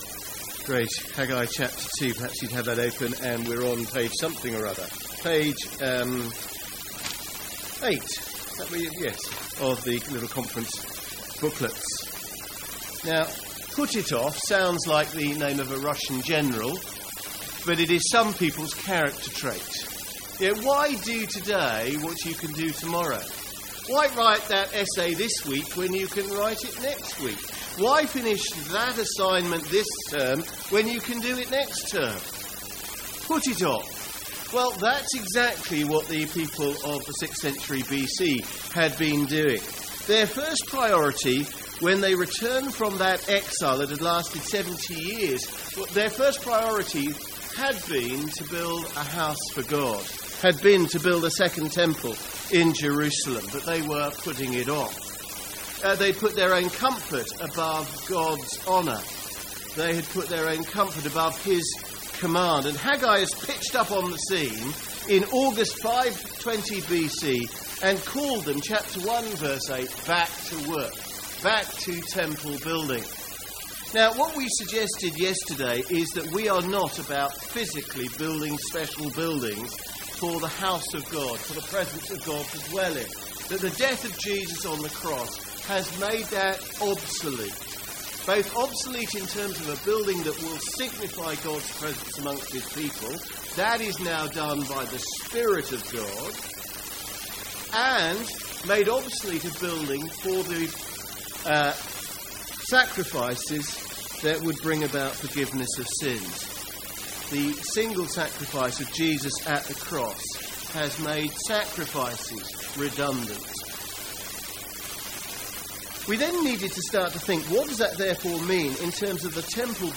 The third talk on Haggai from MYC 2017.